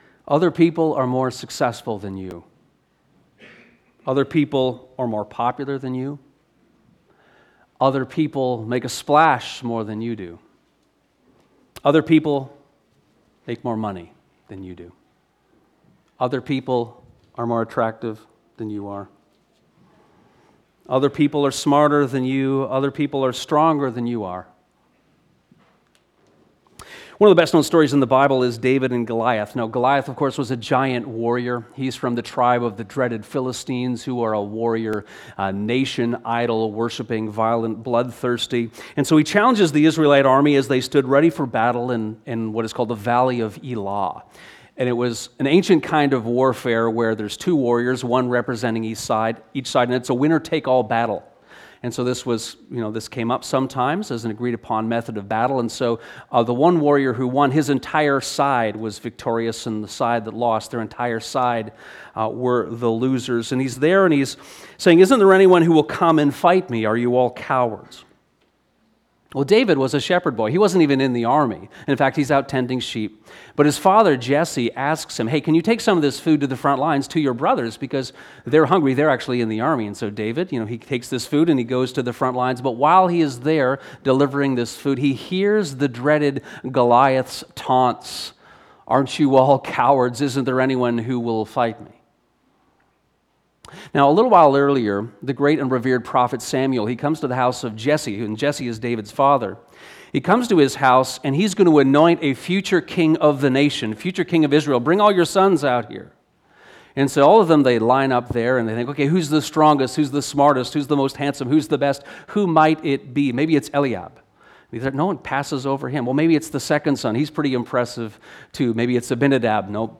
This sermon explores the Great Commandment and also the offering of the poor widow in Mark 12:28-44.